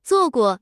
tts_result_12.wav